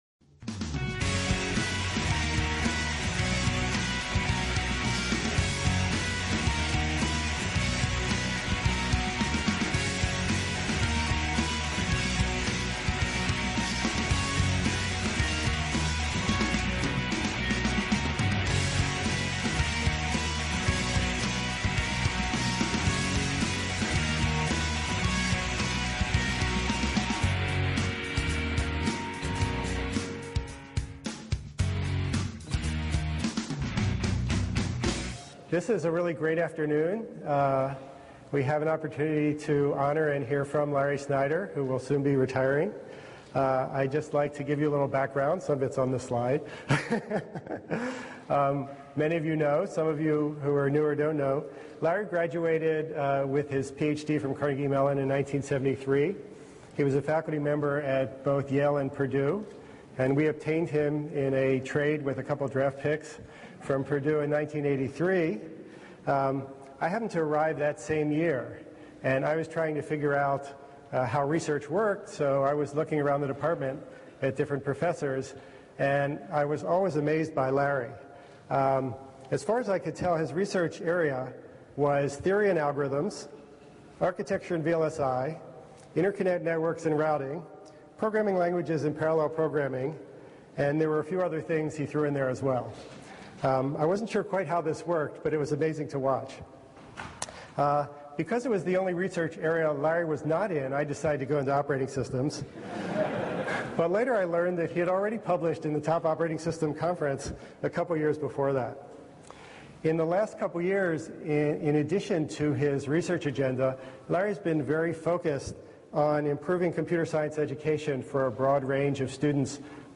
Atrium, Paul G. Allen Center for Computer Science & Engineering
CSE 520 Colloquium Thursday, June 3, 2010, 4:30pm Atrium, Paul G. Allen Center for Computer Science & Engineering Abstract NOTE: this talk will not be broadcast live.